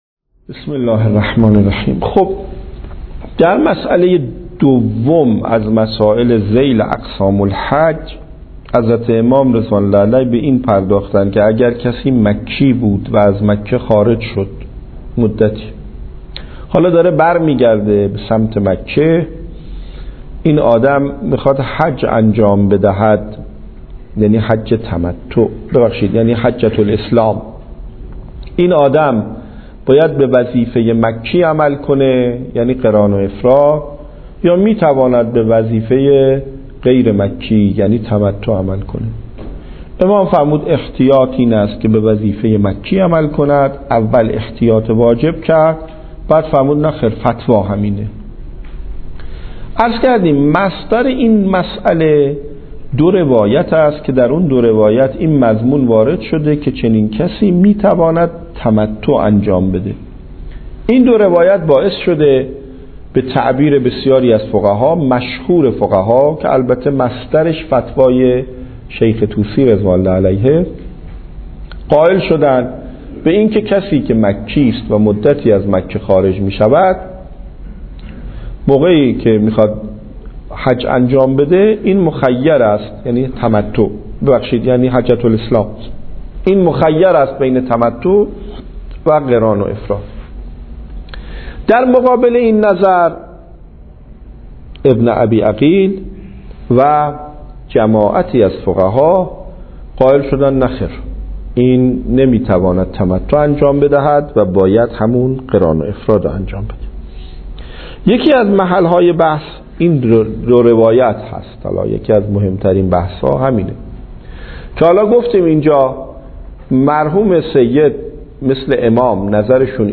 درس خارج فقه